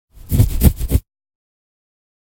scratch.ogg.mp3